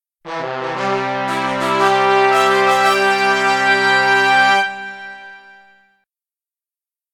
非循环音(SE)